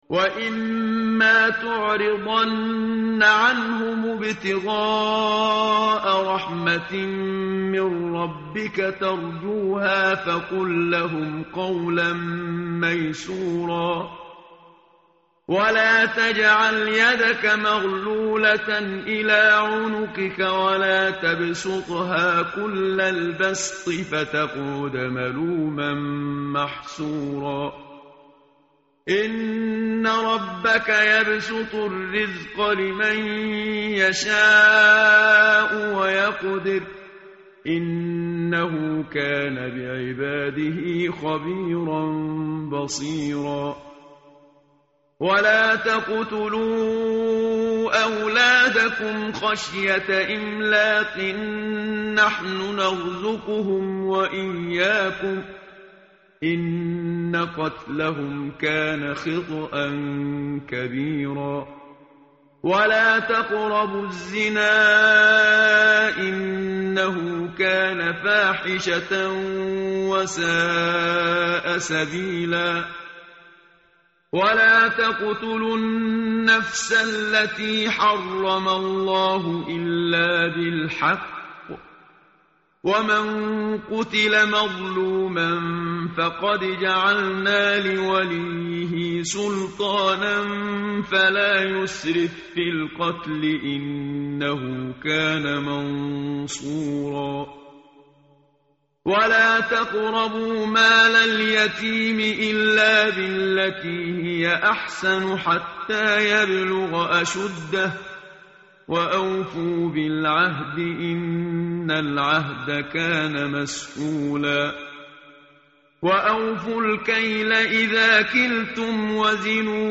متن قرآن همراه باتلاوت قرآن و ترجمه
tartil_menshavi_page_285.mp3